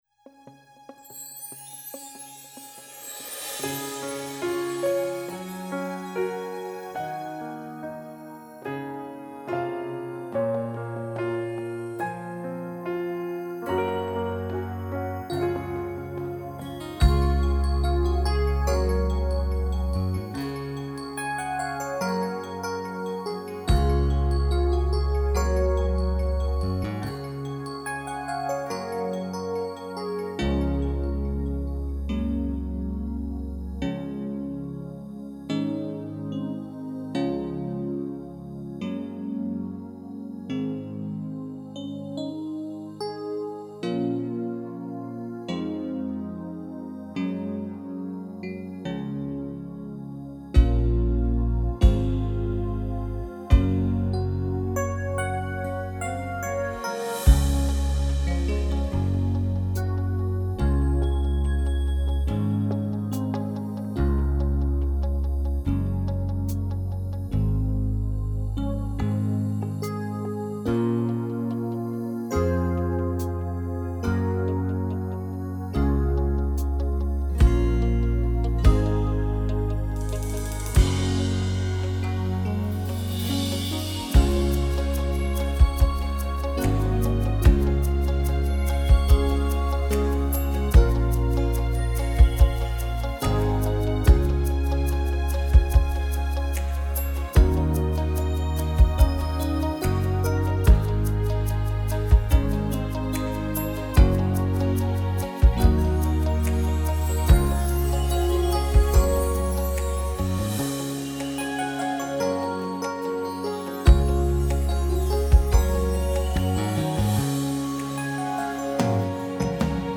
פלייבק .mp3